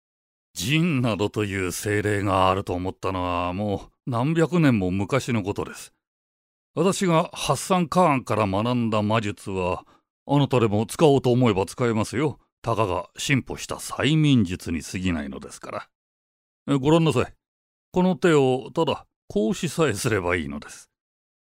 どことなく和を感じさせる低音。
【声優ボイスサンプル】
ボイスサンプル4（インテリ） [↓DOWNLOAD]